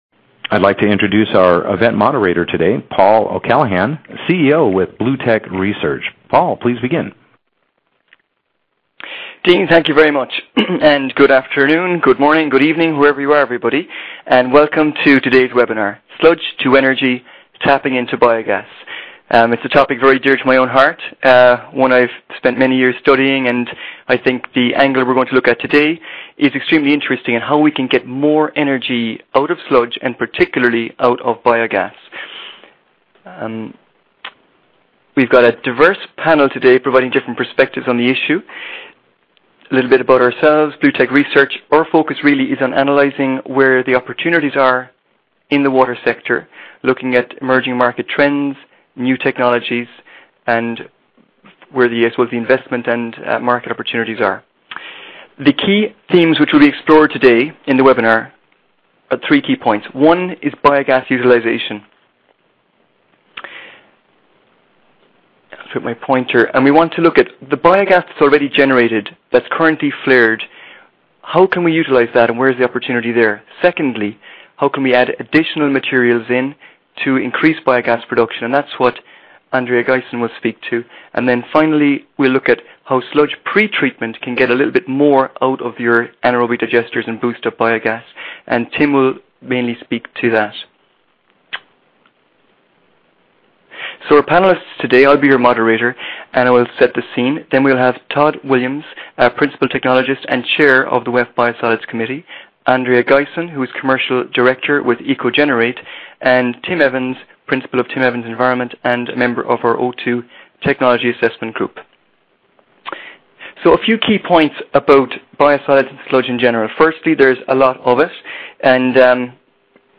BlueTech Webinar_Sludge to Energy: Tapping into Biogas – A review of Economic Feasibility, Market Opportunity & Enabling Technologies